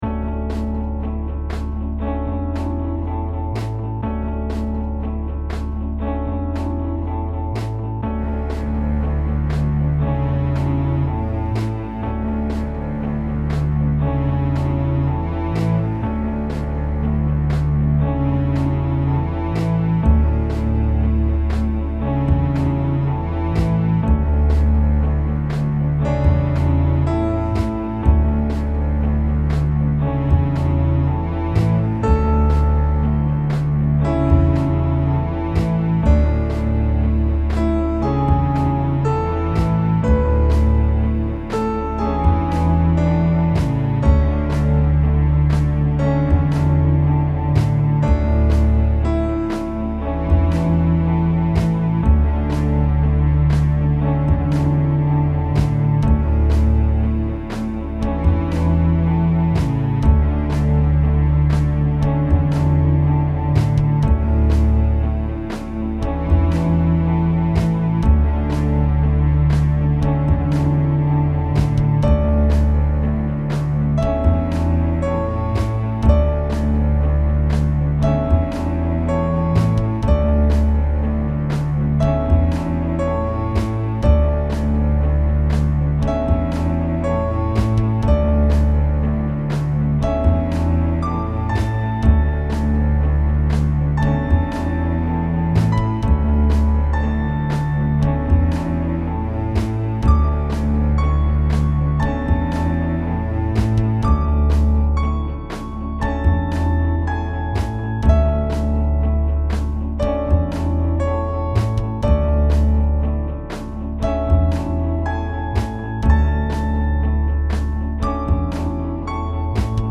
Attached is the latest version, with strings, and a piano track.